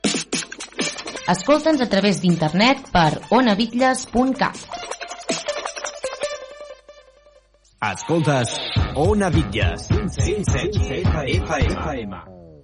a59d542da52b6dd31a8f7f675f5f4e45c1d3358b.mp3 Títol Ona Bitlles Emissora Ona Bitlles Titularitat Pública municipal Descripció Indicatius de l'emissora per internet i FM.